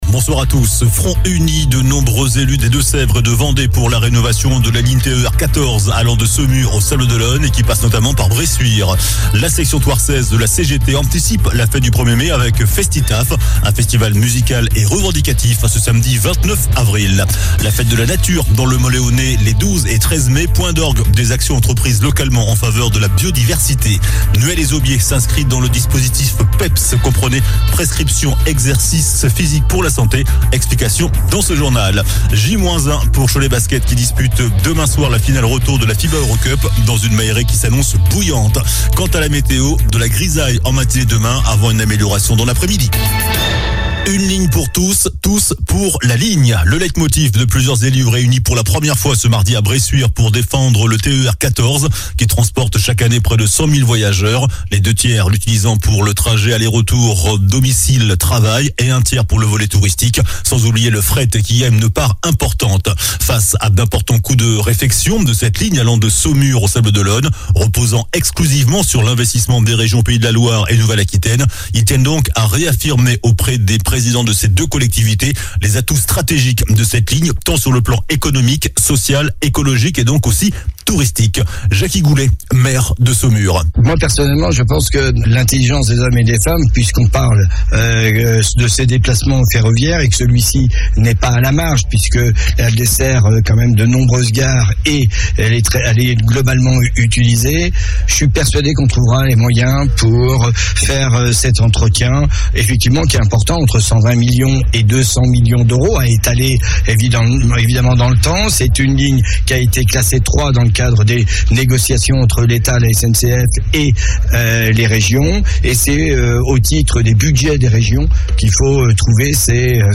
JOURNAL DU MARDI 25 AVRIL ( SOIR )